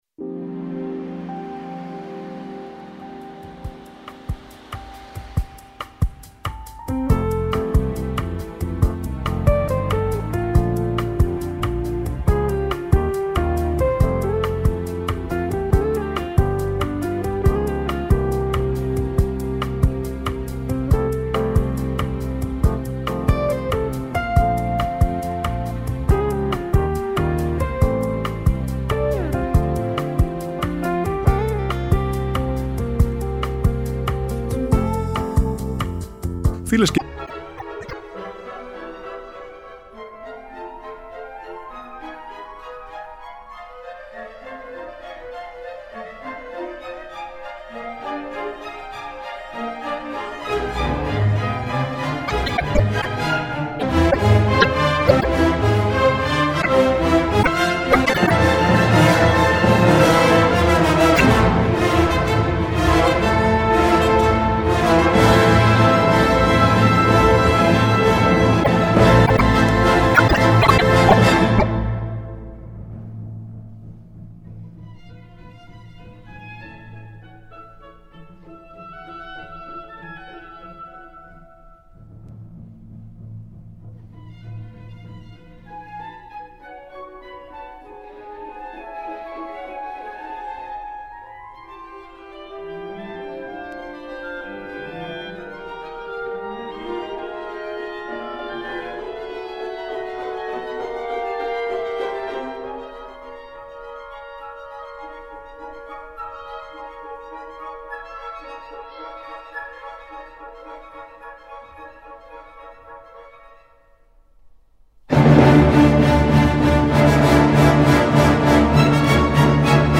συμφωνικά έργα